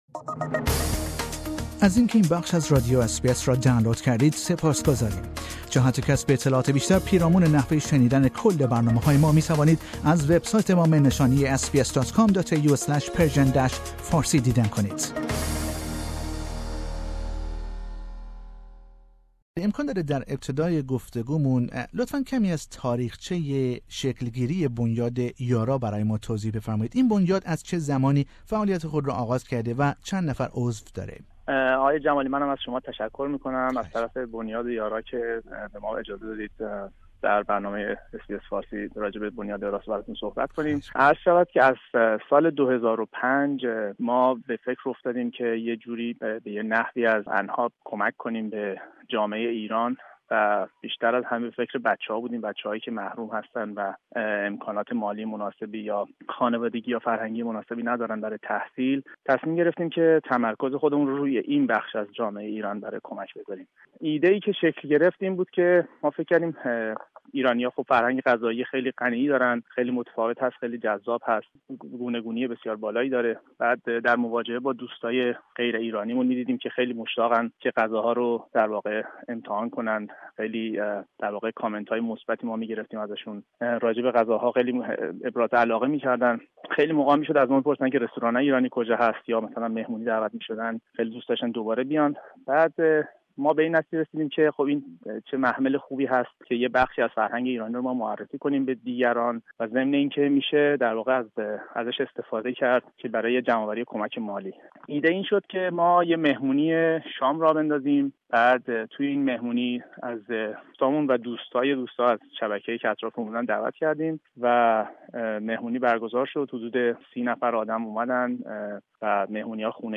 در گفتگو با بخش فارسی رادیو اس بی اس